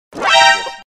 Hiệu ứng âm thanh MGS ALERT - Hiệu ứng âm thanh edit video